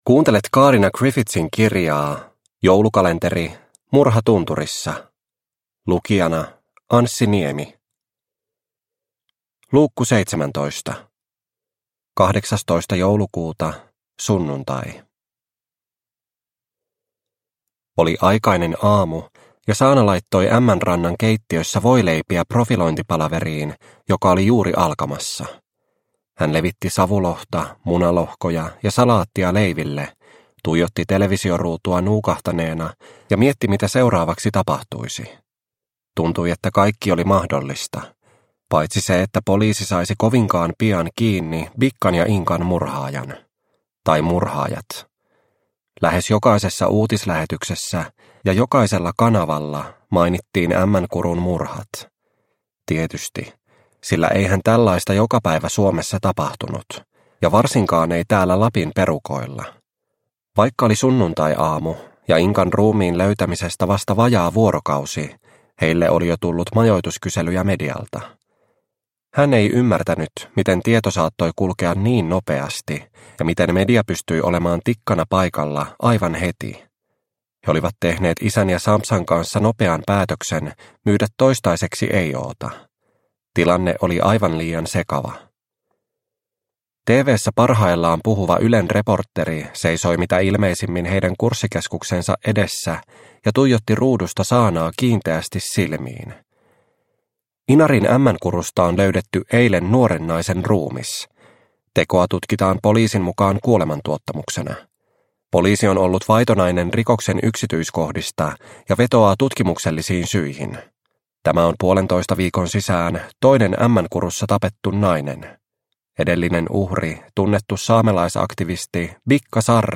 Murha tunturissa - Osa 17 – Ljudbok – Laddas ner